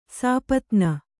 ♪ sāpatna